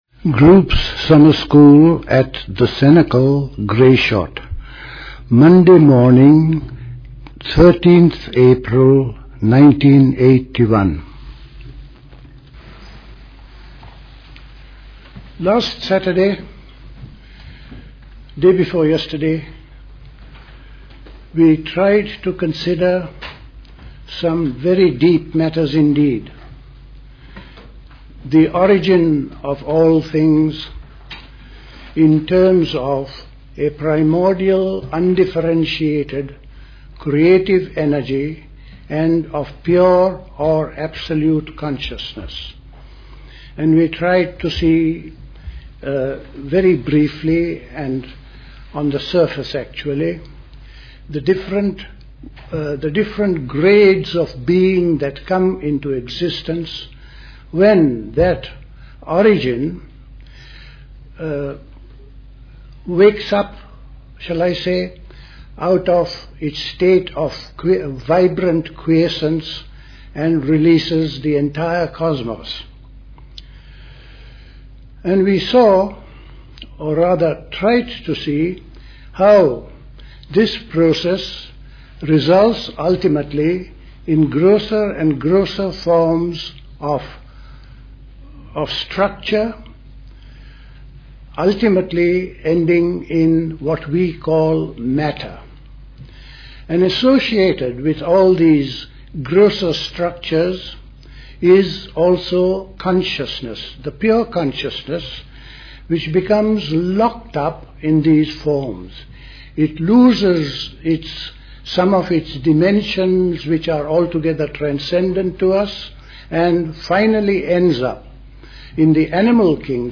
Play Talk
The Cenacle Summer School Talks